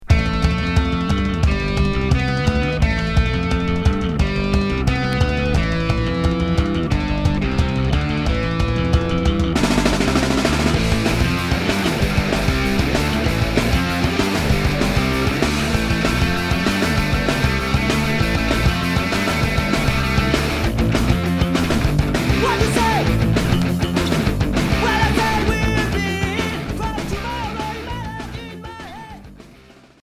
Skatecore